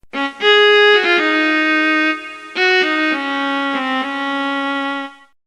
Viola Sound Effect No Copyright
viola-sound-effect-no-copyright.mp3